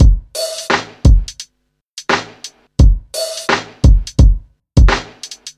嘻哈鼓循环
Tag: 86 bpm Hip Hop Loops Drum Loops 961.66 KB wav Key : Unknown FL Studio